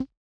Index of /90_sSampleCDs/300 Drum Machines/Hammon Auto-Vari 64/Hammond Auto-Vari 64 Ableton Project/Samples/Recorded